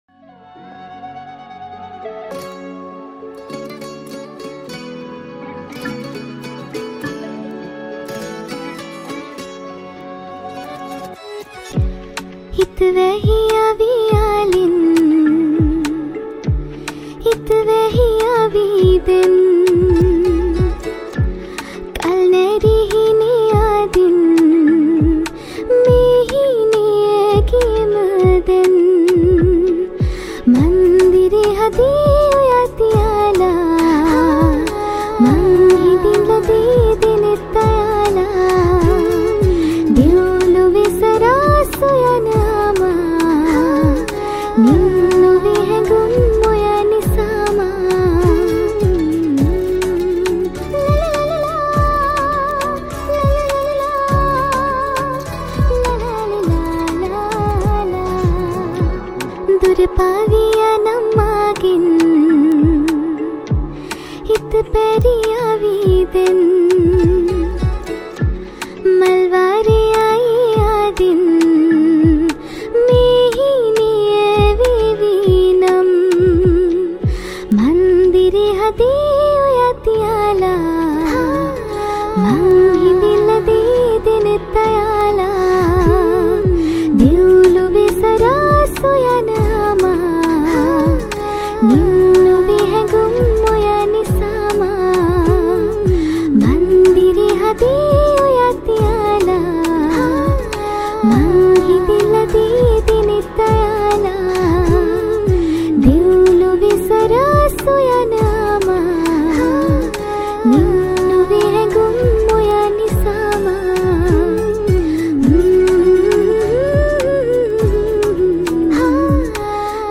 Backing Vocals
Mandolin